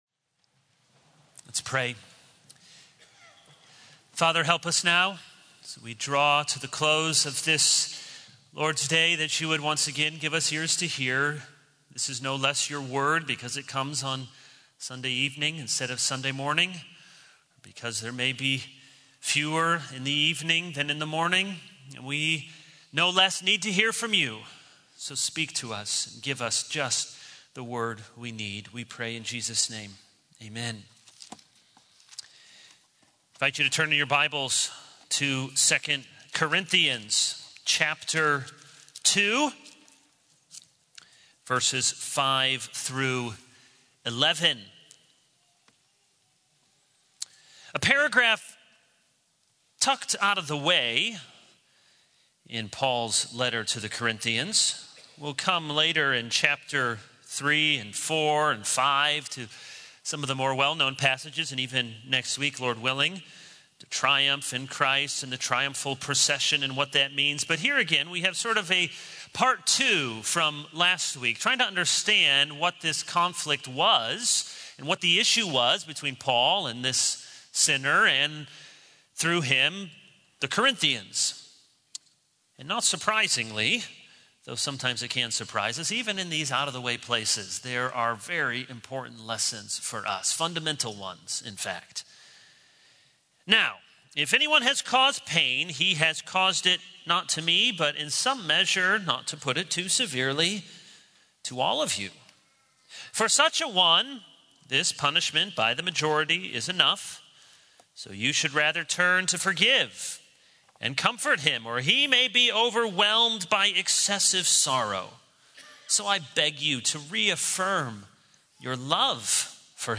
This is a sermon on 2 Corinthians 2:5-11.